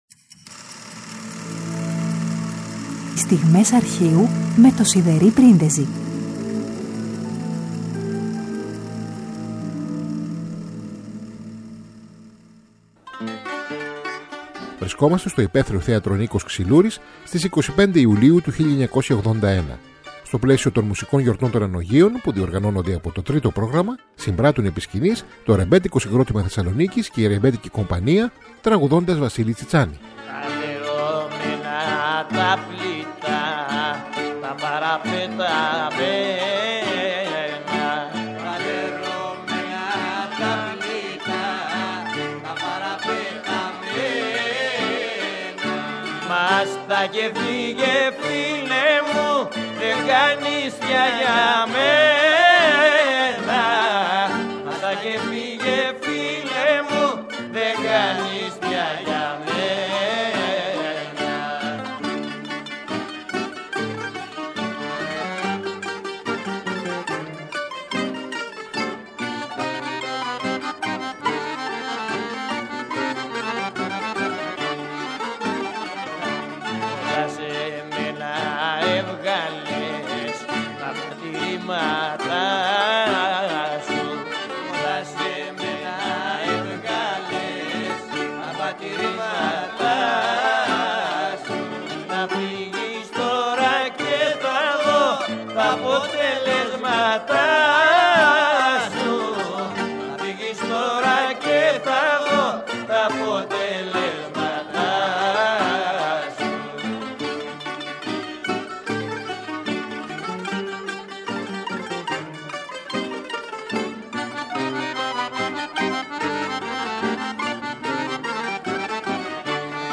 Παρασκευή 18 Φεβρουαρίου: Βρισκόμαστε στο υπαίθριο θέατρο «Νίκος Ξυλούρης» στις 25 Ιουλίου 1981. Στο πλαίσιο των Μουσικών Γιορτών Ανωγείων που διοργανώνονται από το Τρίτο Πρόγραμμα, συμπράττουν επί σκηνής το Ρεμπέτικο Συγκρότημα Θεσσαλονίκης και η Ρεμπέτικη Κομπανία τραγουδώντας Βασίλη Τσιτσάνη.